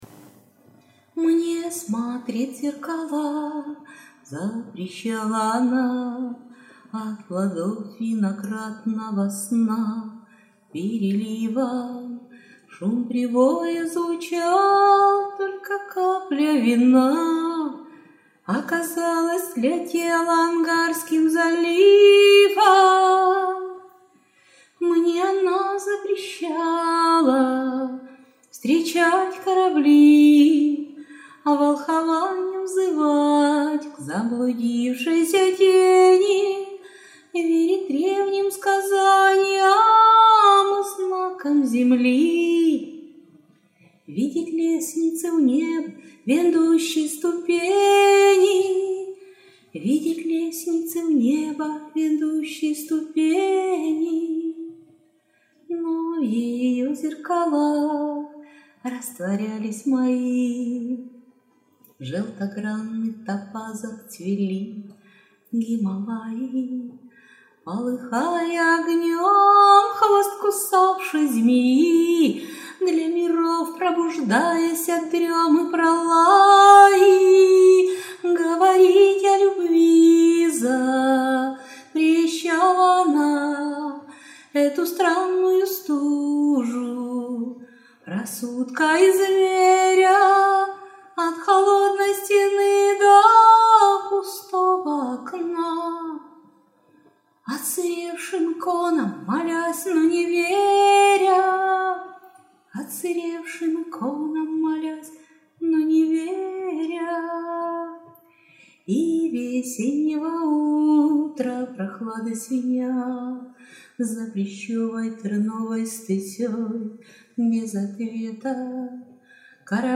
Гарний голос 39 !